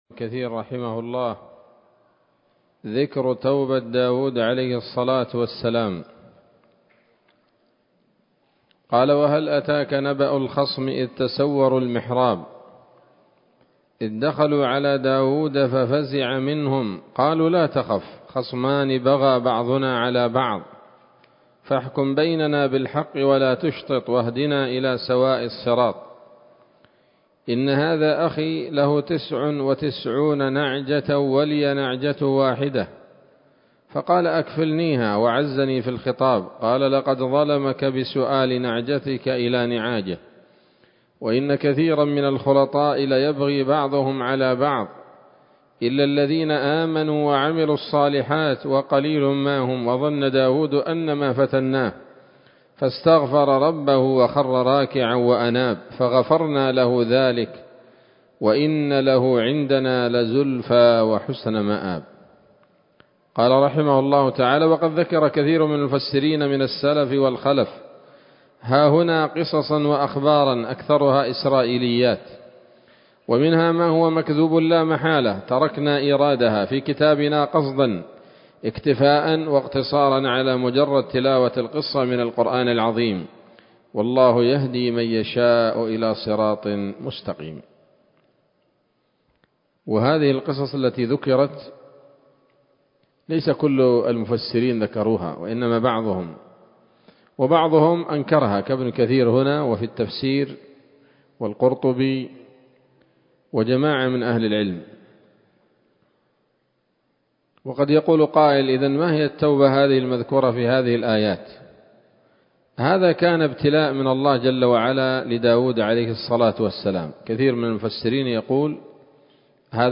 ‌‌الدرس التاسع عشر بعد المائة من قصص الأنبياء لابن كثير رحمه الله تعالى